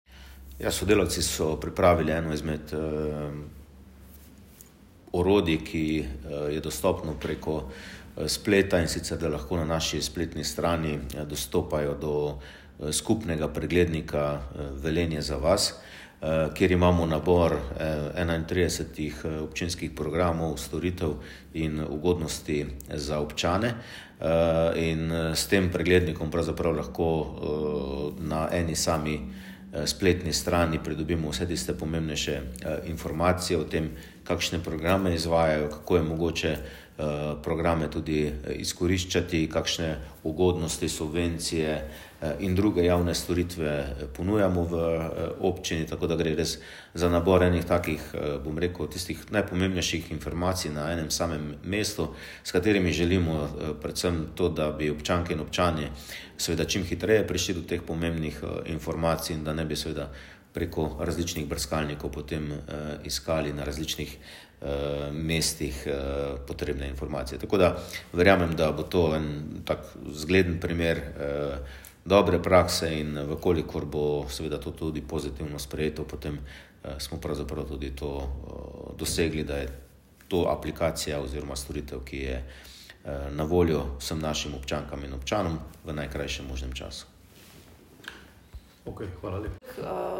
izjavo župana Mestne občine Velenje Petra Dermola.